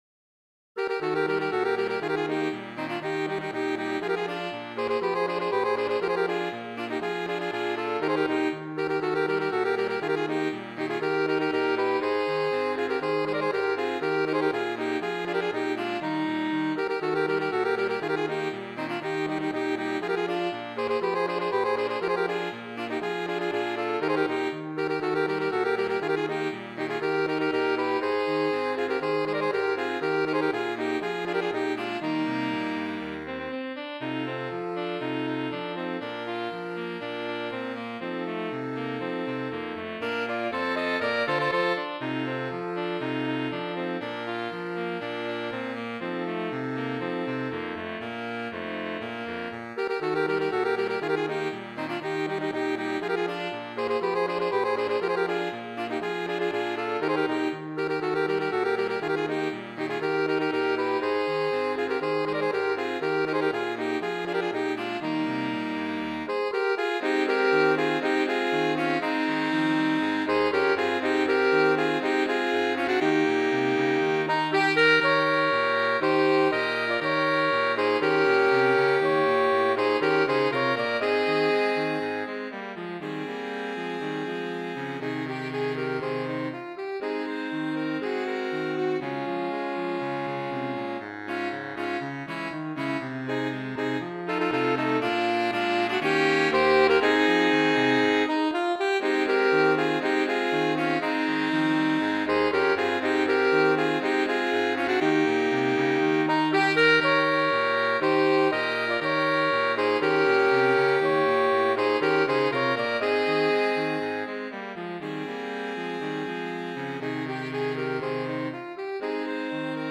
Voicing: Saxophone Quartet (SATB)